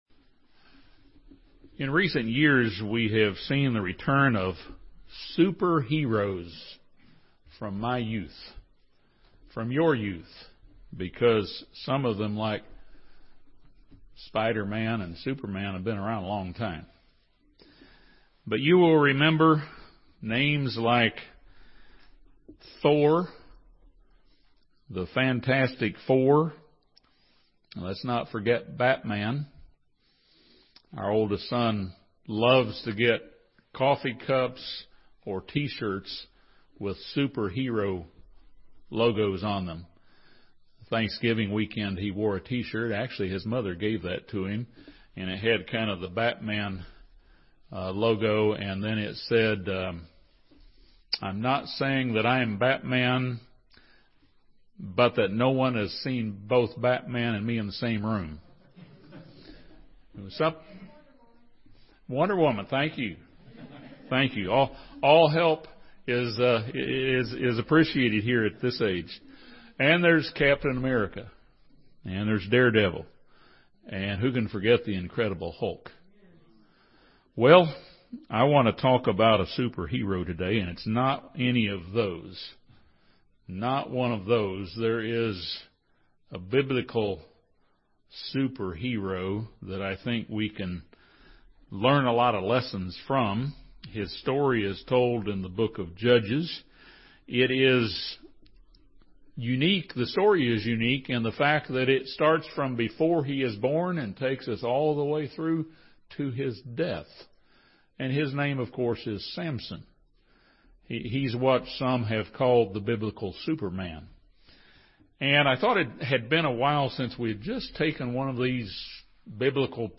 This sermon draws several lessons from the life of Samson.